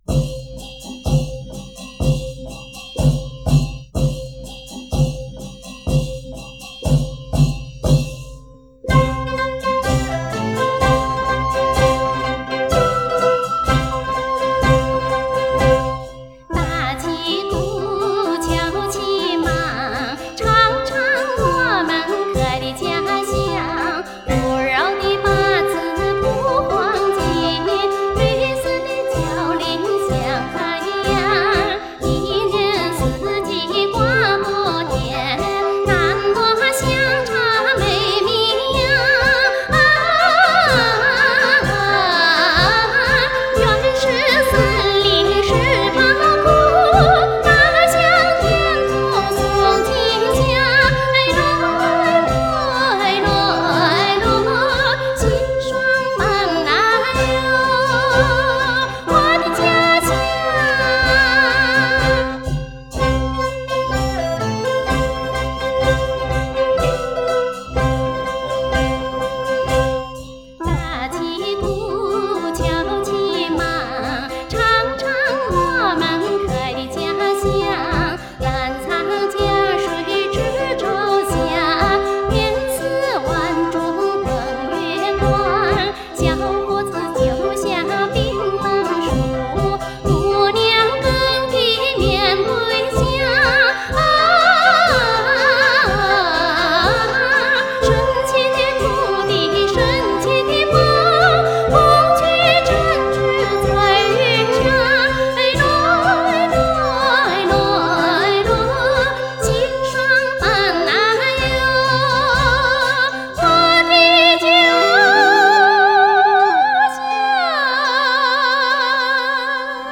云南民歌
这是一张MTV风光、音乐、舞蹈片，1992年在云南西双版纳购买到，因歌曲极具云南少数民族风味及音乐元素，
经提取音轨、两个声道重新混编制作而成。